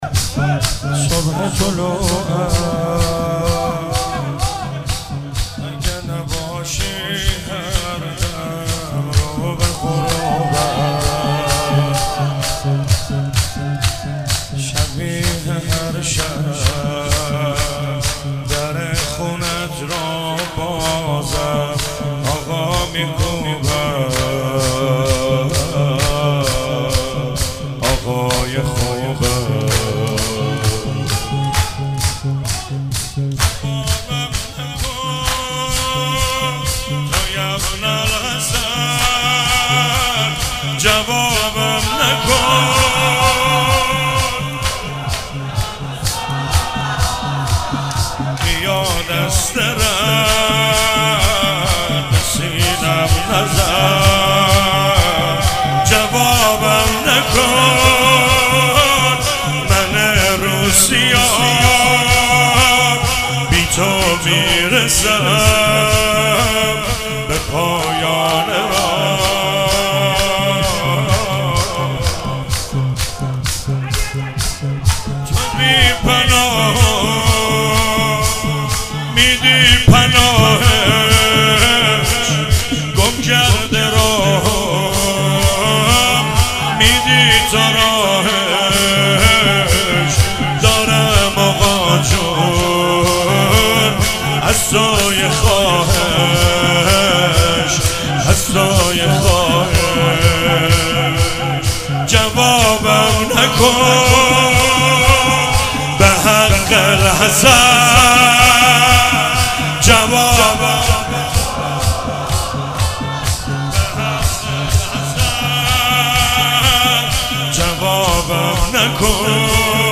هفتگی 27 اردیبهشت 97 - شور - صبح طلوعم اگه نباشی